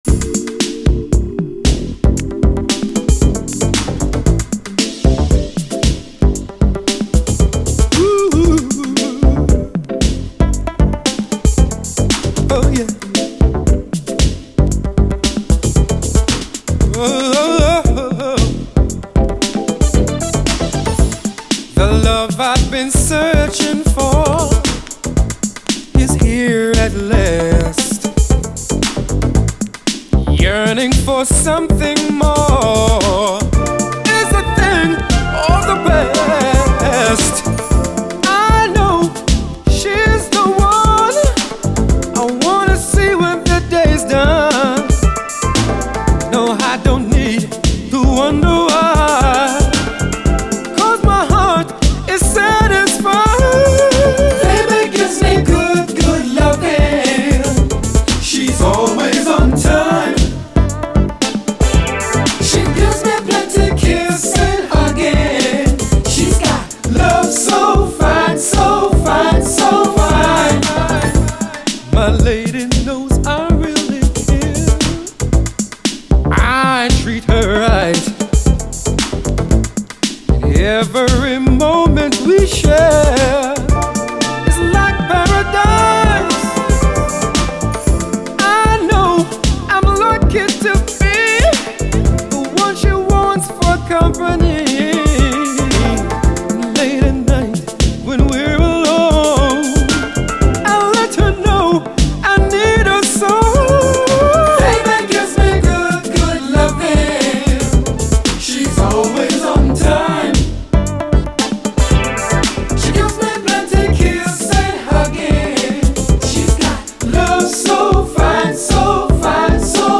SOUL, 70's～ SOUL, DISCO
深く黒く美しいメロウ・UKストリート・ソウル！
深く黒く美しいメロウ・マシーン・ソウルです！